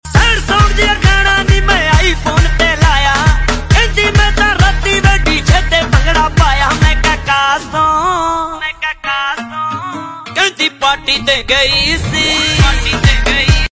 Slow Reverb